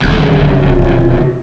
Cri de Gigalithe dans Pokémon Noir et Blanc.